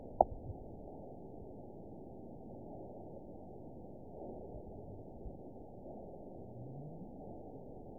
event 917824 date 04/18/23 time 12:35:53 GMT (2 years ago) score 9.32 location TSS-AB01 detected by nrw target species NRW annotations +NRW Spectrogram: Frequency (kHz) vs. Time (s) audio not available .wav